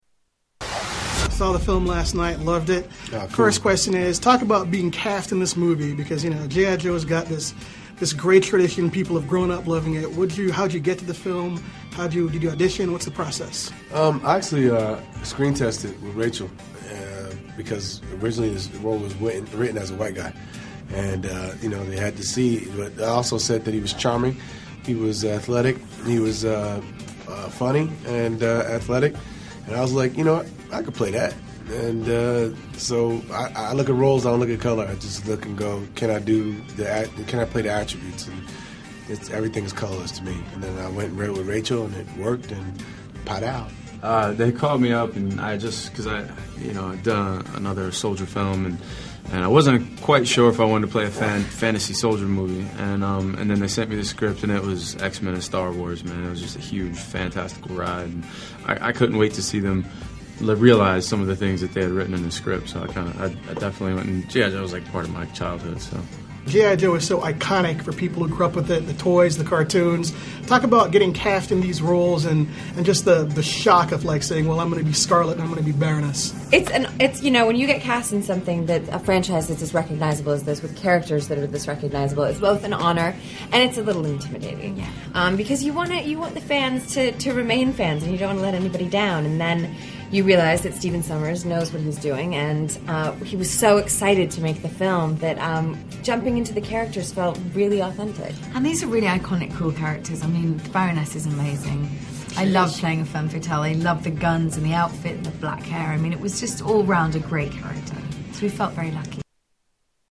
Cast Interview